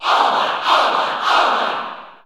Category: Crowd cheers (SSBU) You cannot overwrite this file.
Olimar_Cheer_English_SSB4_SSBU.ogg